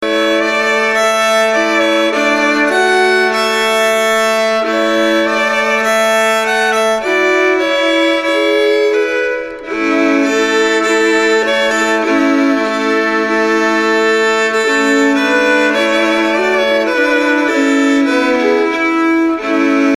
Yuletide music